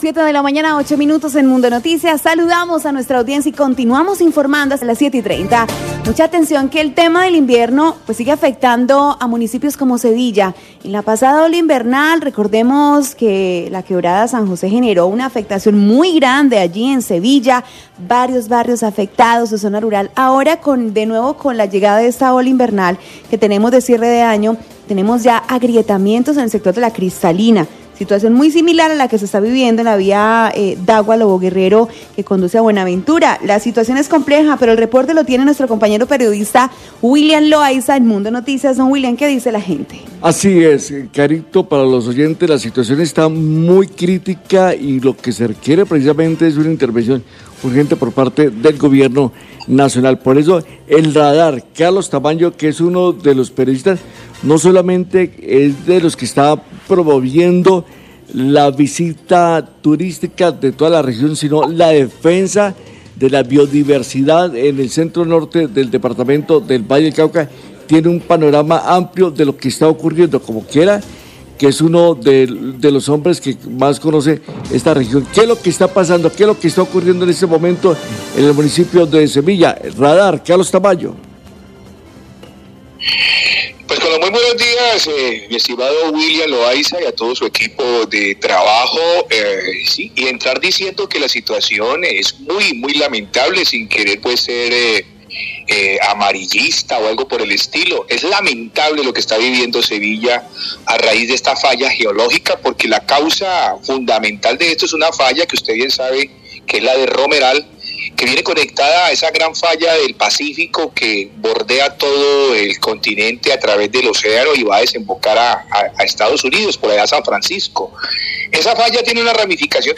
Periodista del municipio de Sevilla explica en Mundo 89Fm como se viene viendo afectada la vía principal del municipio debido a la ola invernal. Según el periodista, Sevilla está a punto de quedar incomunicada y le cabe responsabilidad a la CVC que ha descuidado a la zona en el trabajo de reforestación.